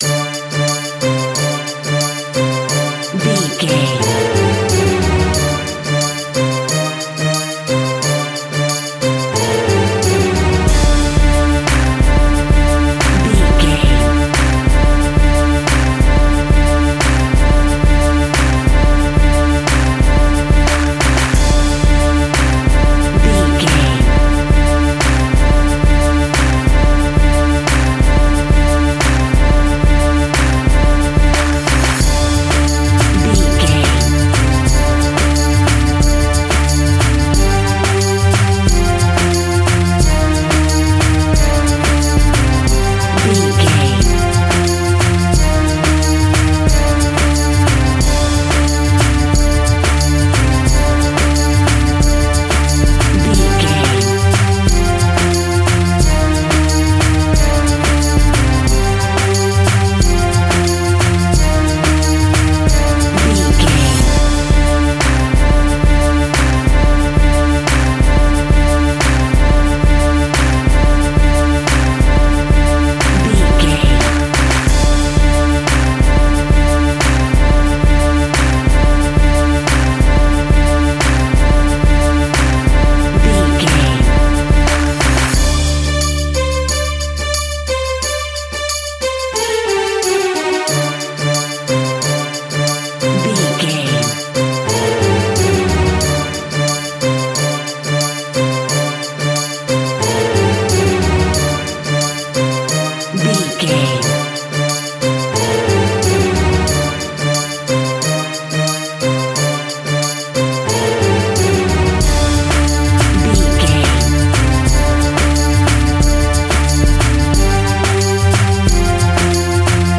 Aeolian/Minor
World Music
percussion
congas
bongos
kora
djembe
kalimba
marimba